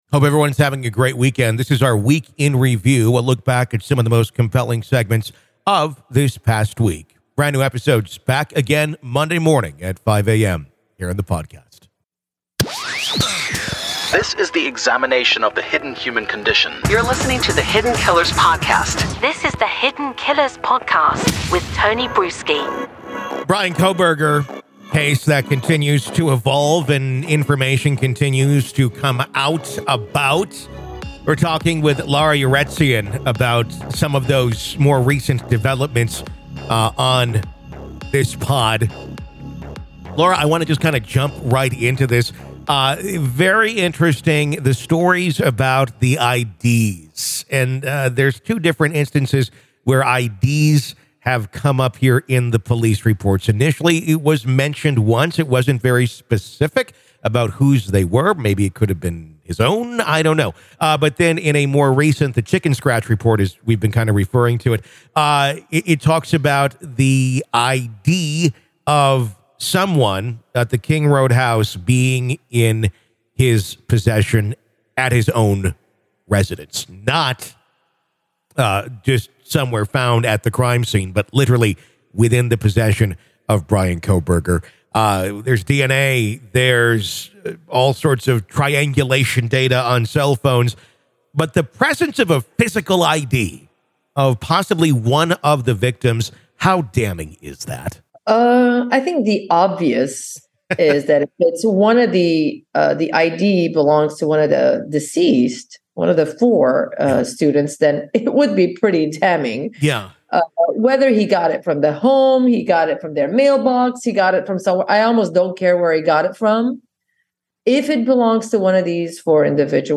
Welcome to "The Week In Review," a riveting journey that takes you back through the most captivating interviews, gripping updates, and electrifying court audio from the cases that have captured our attention.